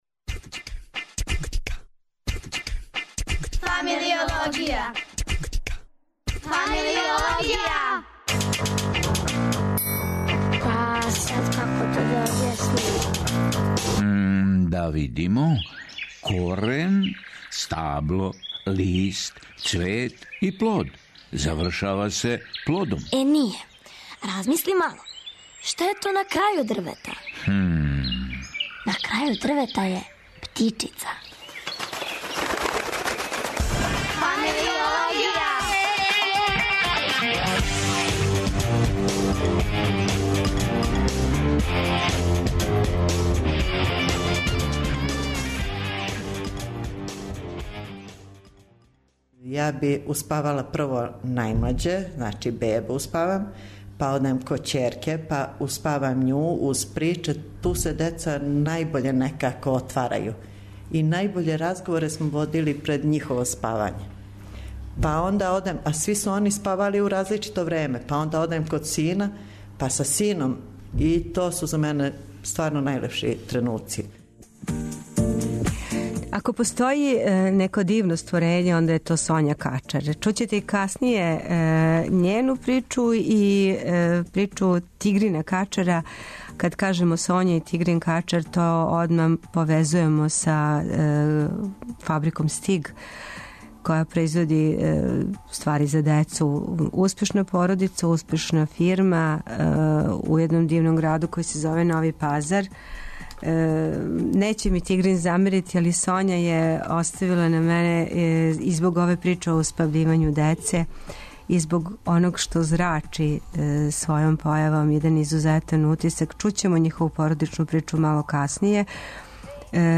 Радио Београд 1, 13.05